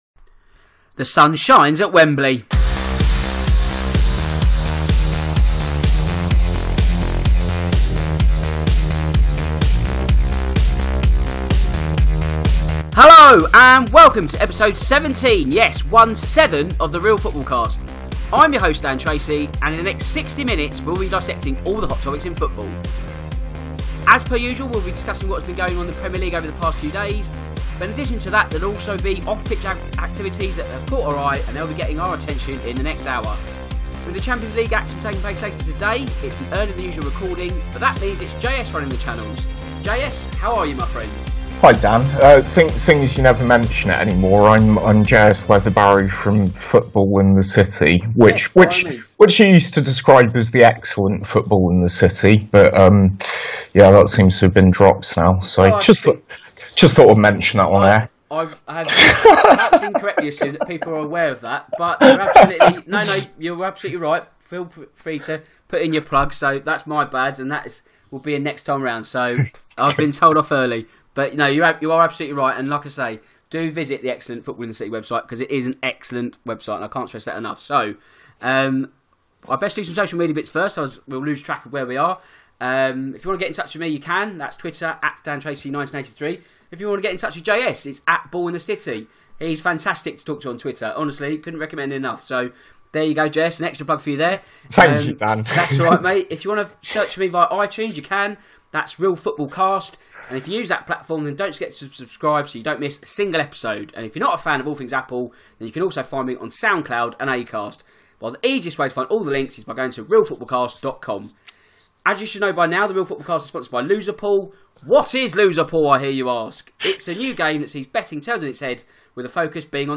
The Real Football Cast returns for another fast paced 60 minutes of Premier League chat.